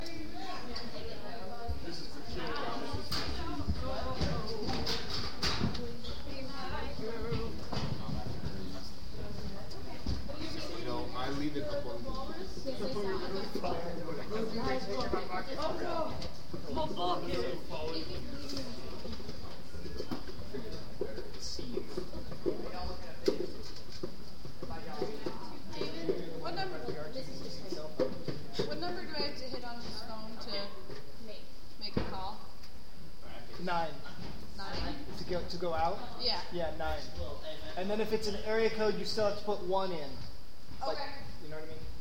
Field Recording #10
SOUND CLIP: Black Box Theatre LOCATION: Black Box Theatre SOUNDS HEARD: people talking, people singing, chairs moving, chairs being folded, footsteps